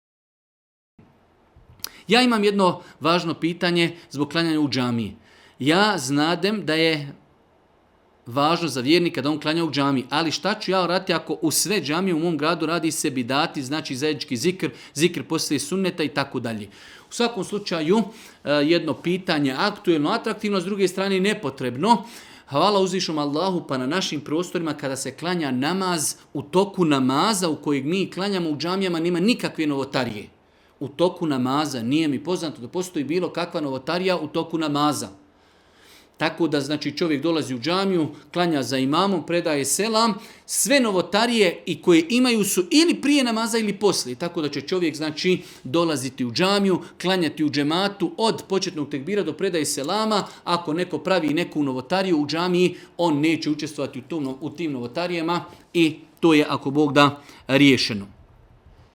Audio isječak odgovora Tvoj web preglednik ne podrzava ovaj fajl, koristi google chrome.